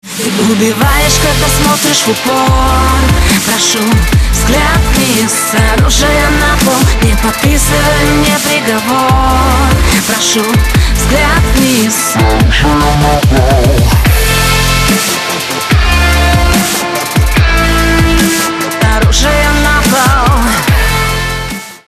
• Качество: 128, Stereo
поп
громкие